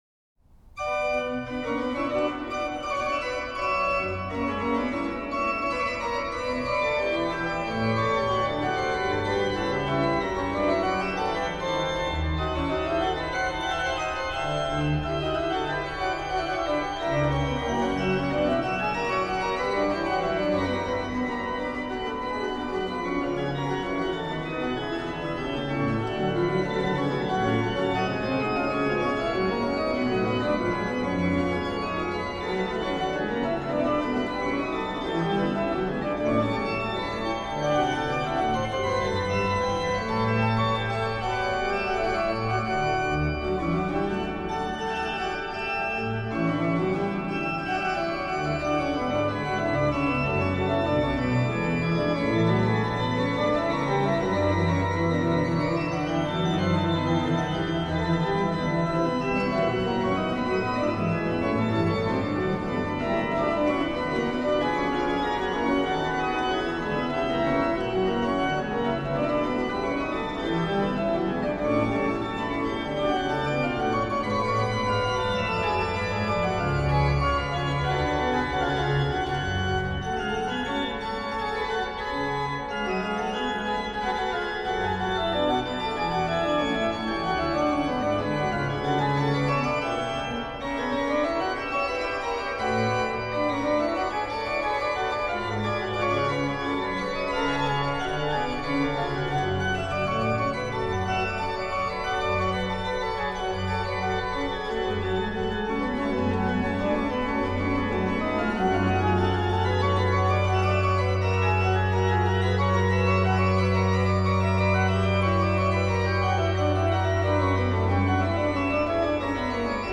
rh: HW: Rfl8, Spz4, Oct2
lh: BW: Pr4, Oct2, Sfl1 (8ve lower)
Ped: Oct8, Oct4